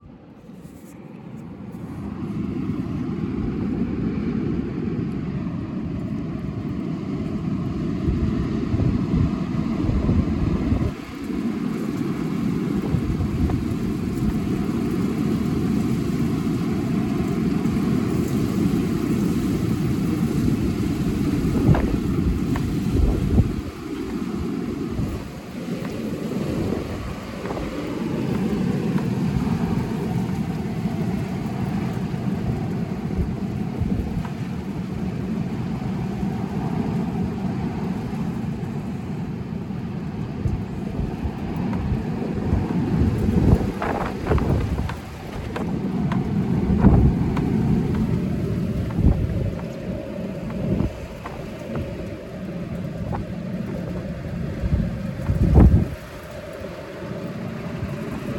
It sounds like we live on a really busy road doesn't it? In reality there's no cars outside and the roads are empty.
Just holding the phone at my window - not even fully open - and this is while it's fairly calm in comparison to when it really whips up.